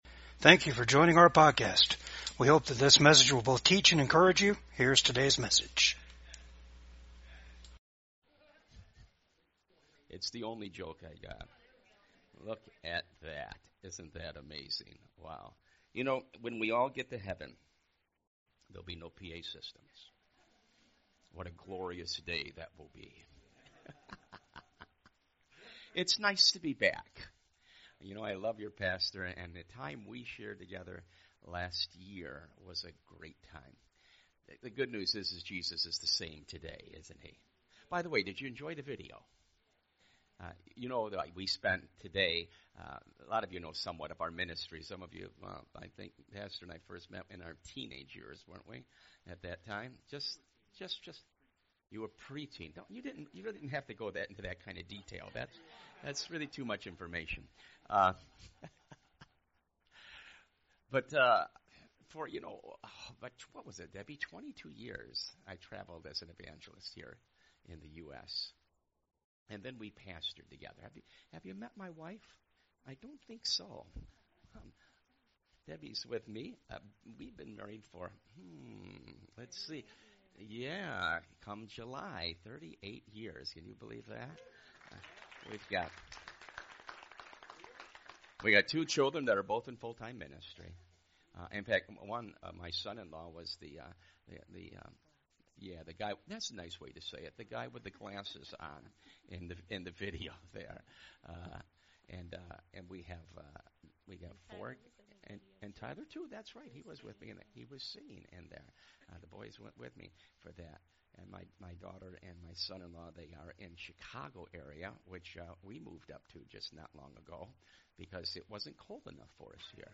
Service Type: REFRESH SERVICE